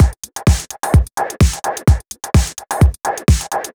VMH1 Minimal Beats 05.wav